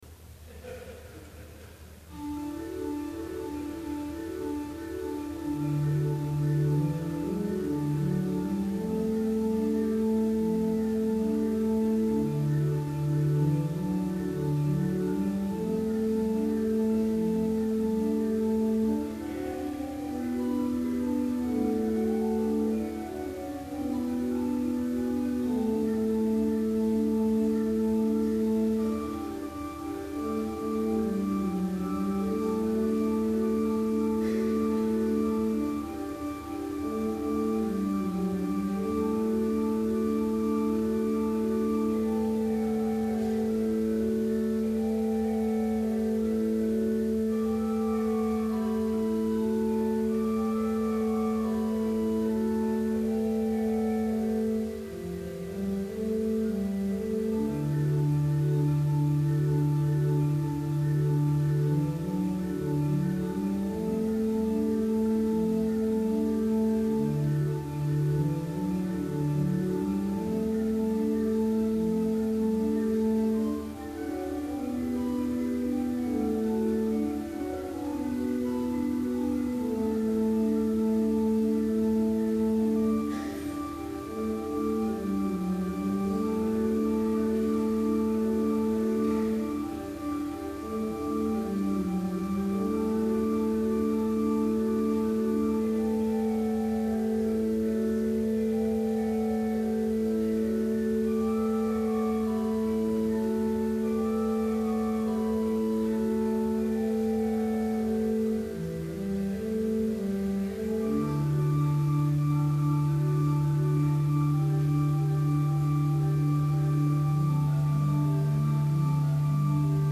Complete service audio for Summer Chapel - May 23, 2012